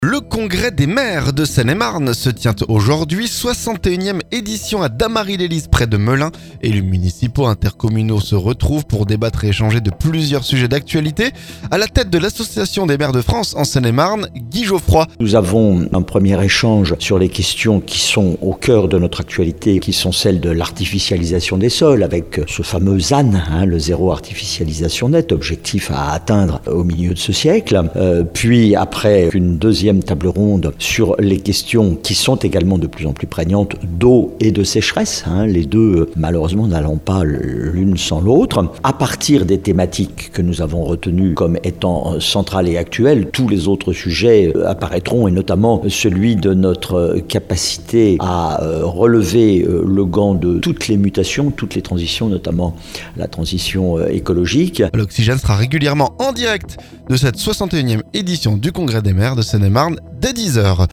Guy Geoffroy, lui même maire de Combs la ville et président de l'Association des maires de France en Seine-et-Marne.